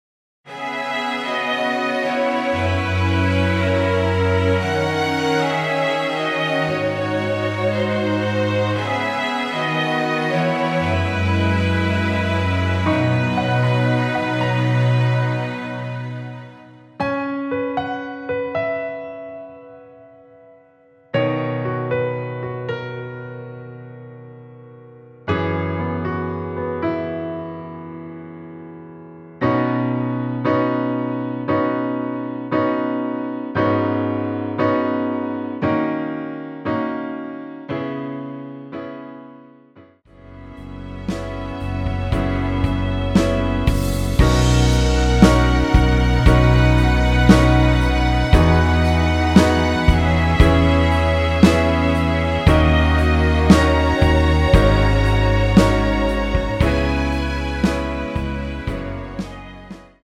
내린 MR
◈ 곡명 옆 (-1)은 반음 내림, (+1)은 반음 올림 입니다.
앞부분30초, 뒷부분30초씩 편집해서 올려 드리고 있습니다.
중간에 음이 끈어지고 다시 나오는 이유는